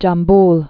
(jäm-bl)